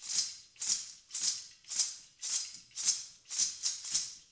APH ANKLEBRC.wav